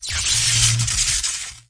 electric.mp3